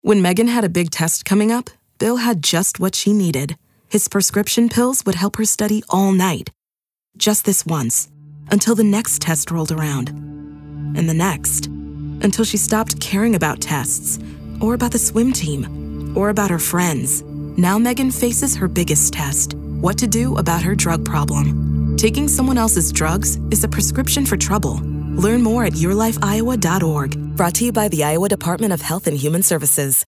:30 Radio Spot | Biggest Test (English and Spanish)
YLI Biggest Test Radio Spot.mp3